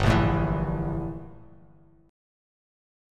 Gm6add9 chord